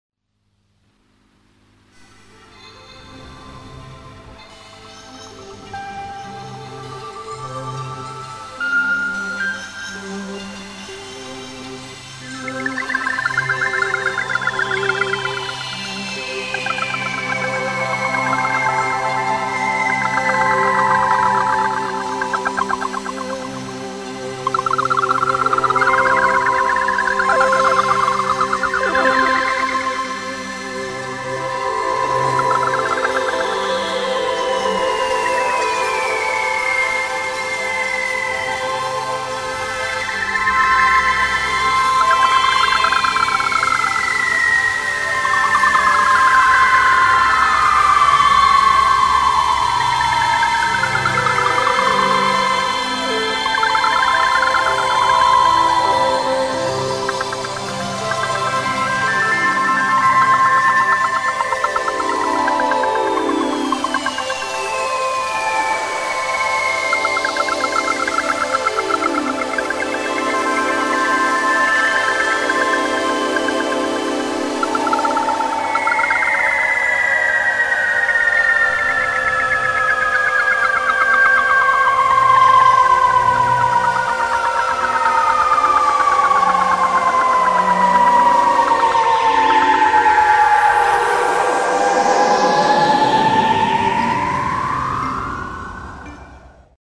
SY22 VECTORサウンド
杜の妖精が次々に舞い降りて戯れています。